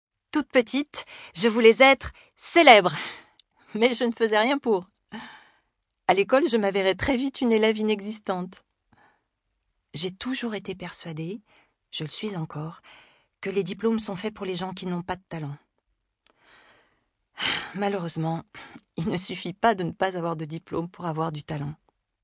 Démo voix - comédie
Voix off
- Mezzo-soprano